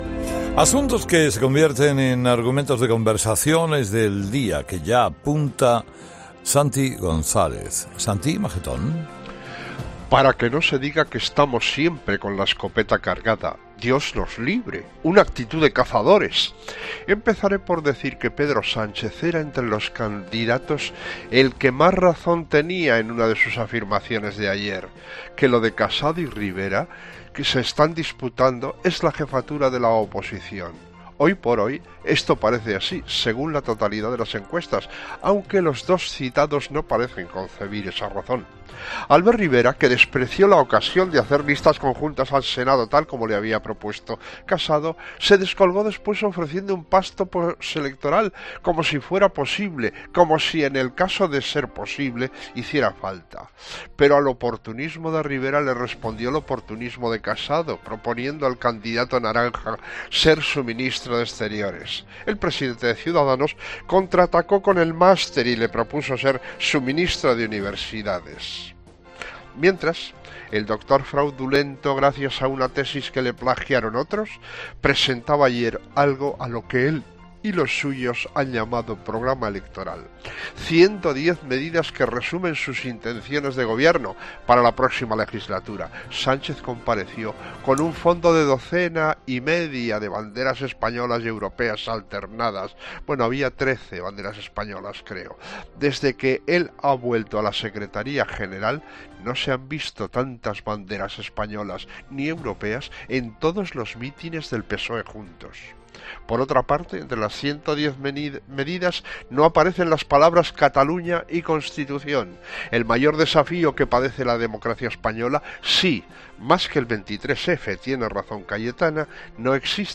El comentario de Santiago González
El análisis de actualidad de Santi González en 'Herrera en COPE'.